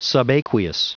Prononciation du mot subaqueous en anglais (fichier audio)